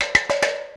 Percussive FX 07 ZG